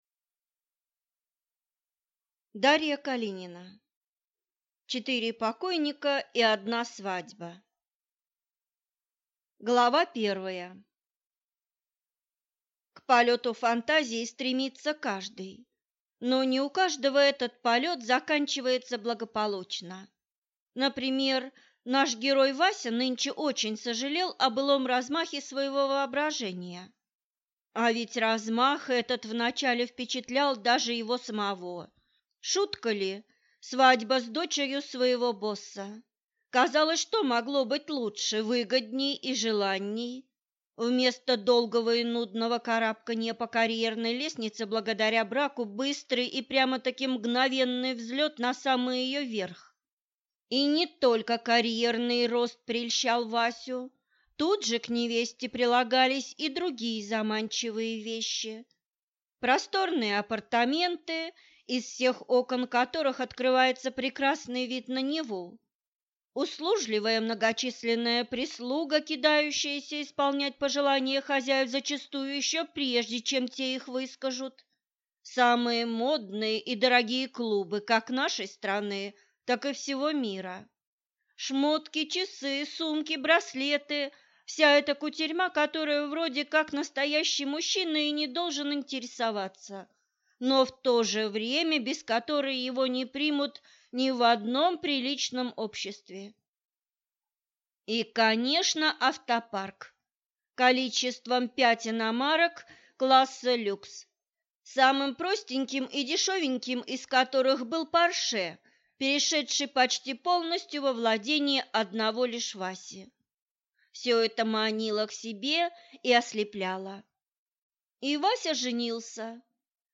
Аудиокнига Четыре покойника и одна свадьба | Библиотека аудиокниг